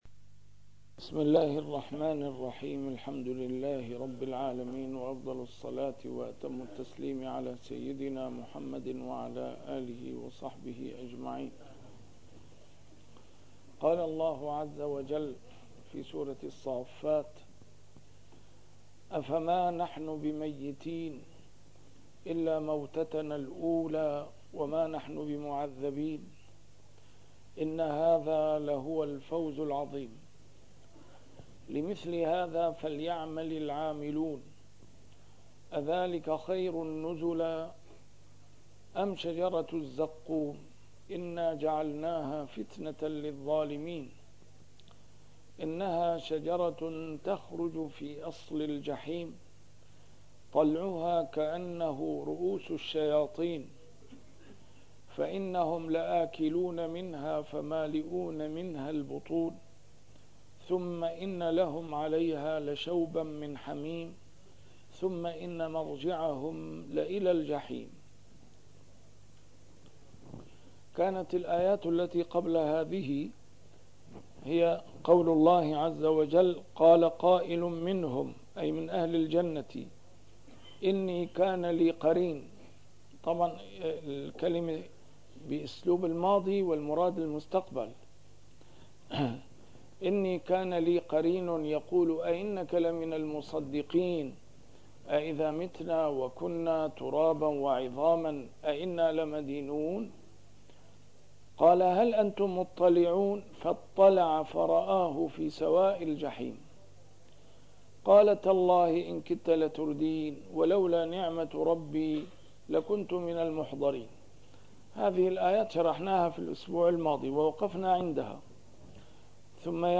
A MARTYR SCHOLAR: IMAM MUHAMMAD SAEED RAMADAN AL-BOUTI - الدروس العلمية - تفسير القرآن الكريم - تسجيل قديم - الدرس 451: الصافات 058-068